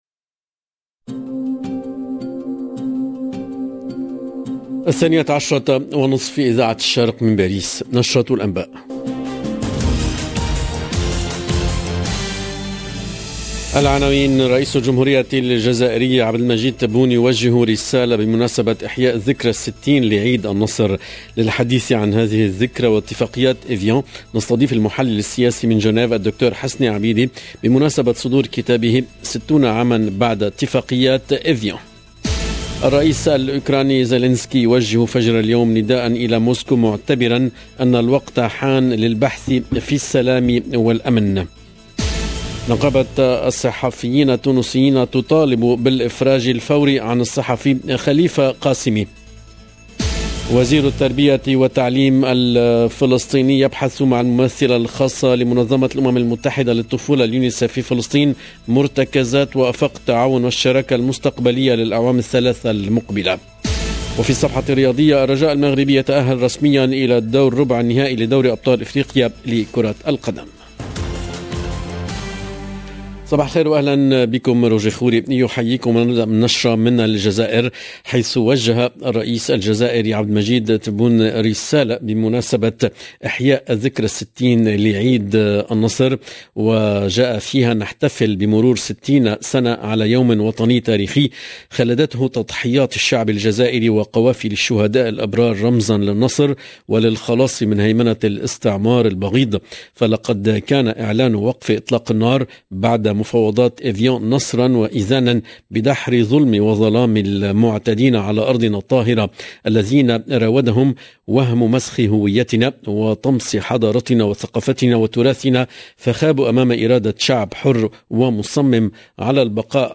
LE JOURNAL DE MIDI 30 EN LANGUE ARABE DU 19/03/22